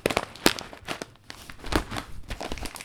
• cofee beans bag asmr.wav
a small Julius Meinl bag of coffee being opened, ready to be grinded, recorded with a TASCAM DR 40.
cofee_beans_bag_asmr_cyC.wav